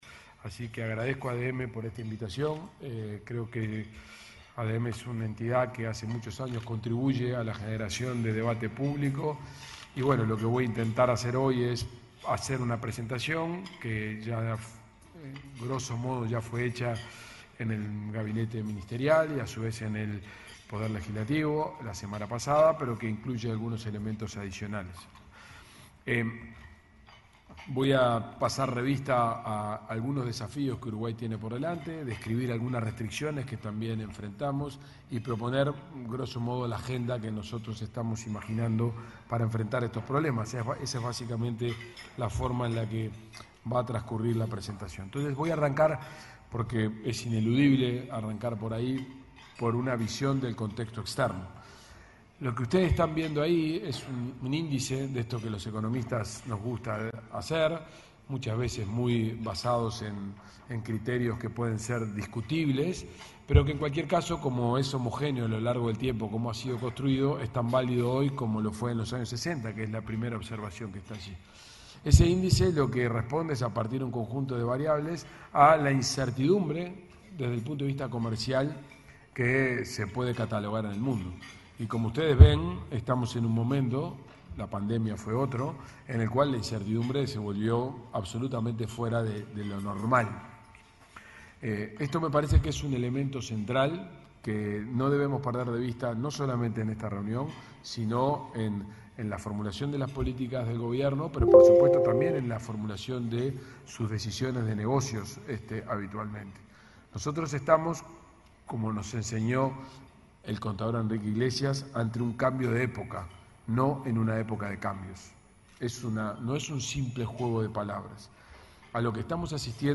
Disertación del ministro de Economía, Gabriel Oddone
El ministro de Economía, Gabriel Oddone, disertó, este martes 8, en el almuerzo de trabajo de la Asociación de Dirigentes de Marketing.